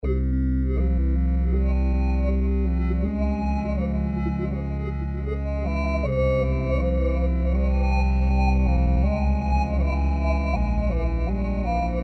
描述：使用Korg AmkII制作。
Tag: 80 bpm Chill Out Loops Synth Loops 2.02 MB wav Key : Unknown